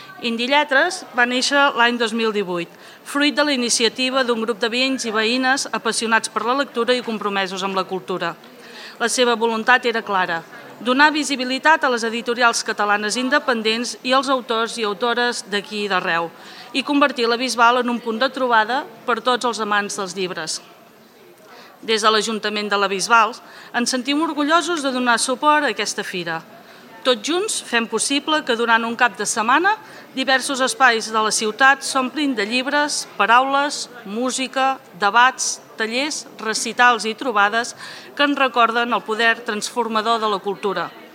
Ho explica la regidora de Cultura, Eva Rovira.